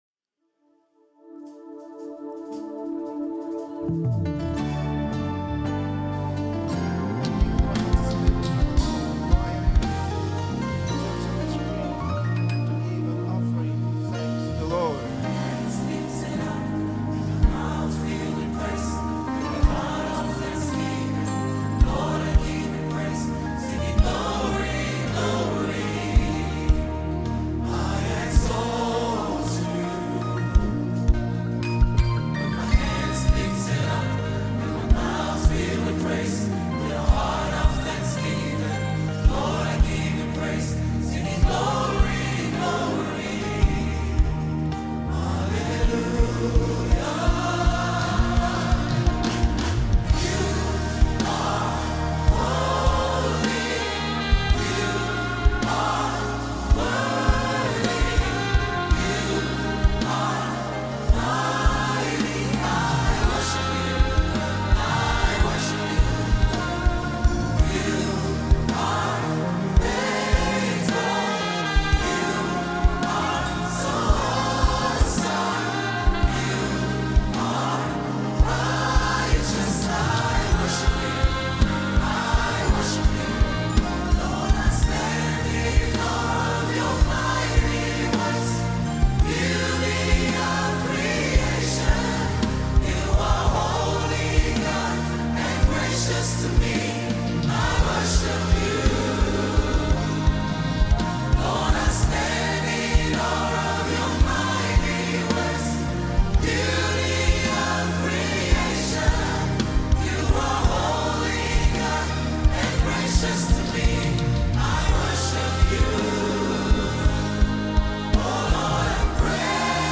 Lyrics, Medleys